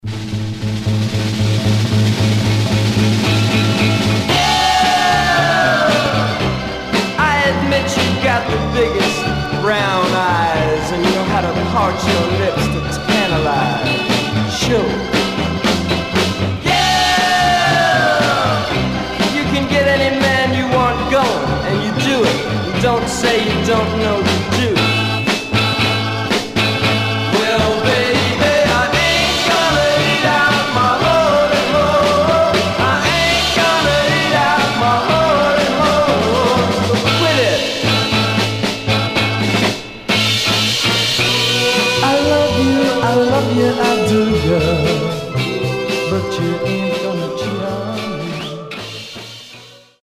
Stereo/mono Mono
Garage, 60's Punk Condition